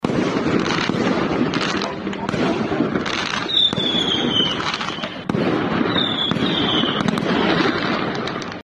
Incluso, Radio Bío Bío recabó información sobre los estrepitosos ruidos que se escucharon.
cu-velorio-chorrillos-ambiente.mp3